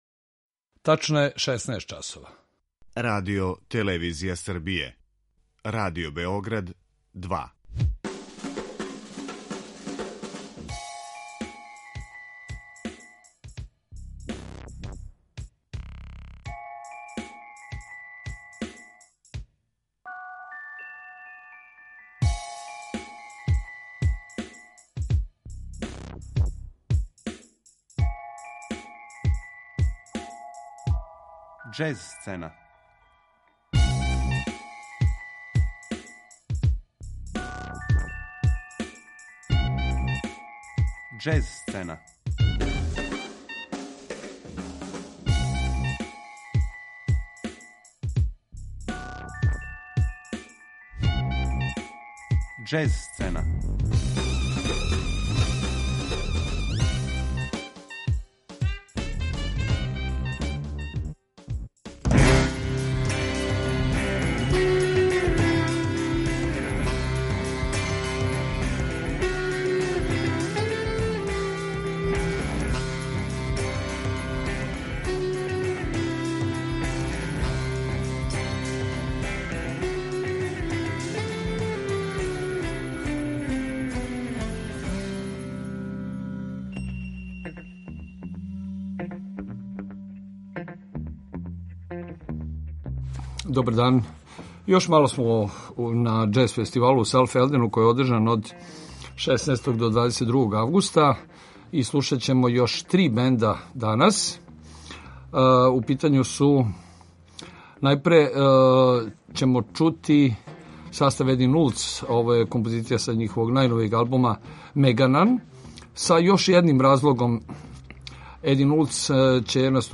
Приказе ових угледних европских манифестација звучно илуструјемо актуелним снимцима учесника.